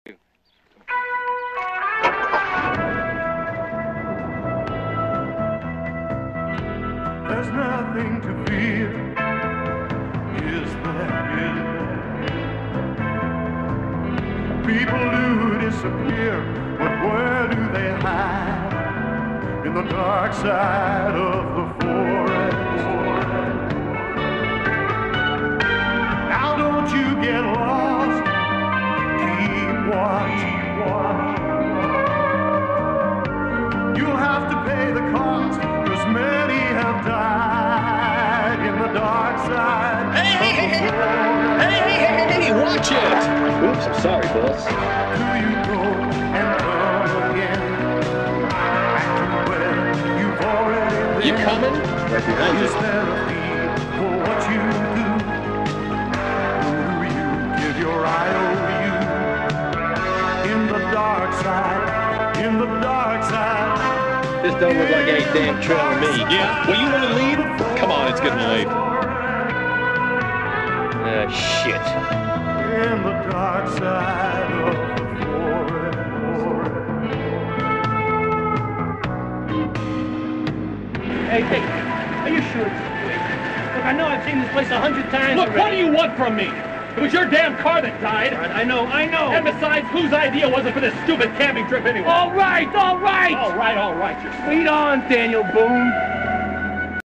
it’s as ridiculous as it sounds